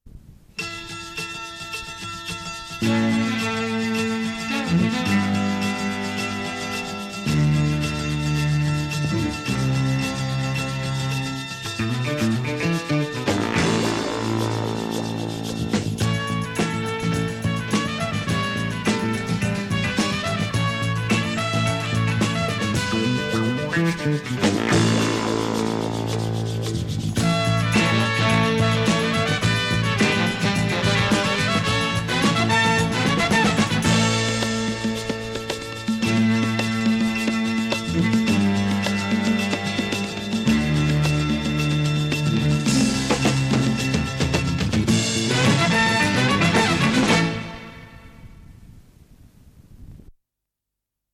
Sintonia de l'emissora
versió "beat"
Estava basada en un toc militar adaptat